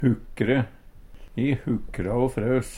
hukkre - Numedalsmål (en-US)